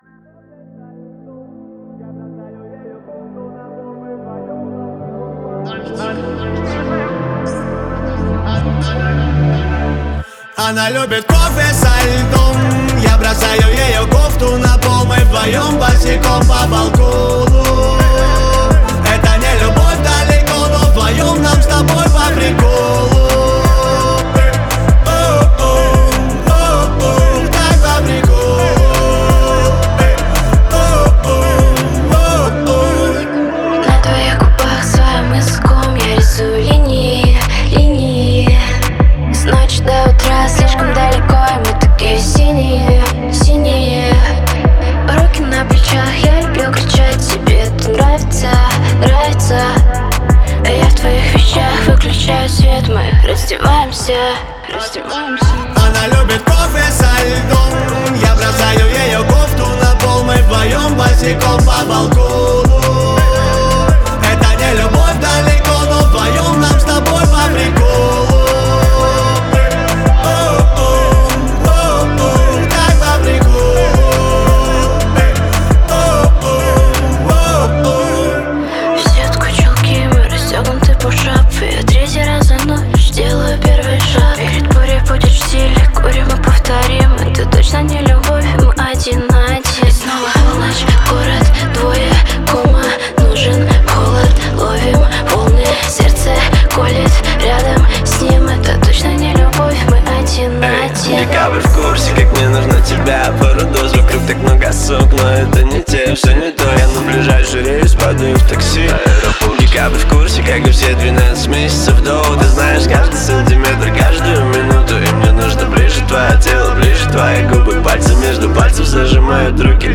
это яркая композиция в жанре поп с элементами R&B